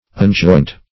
Unjoint \Un*joint"\, v. t.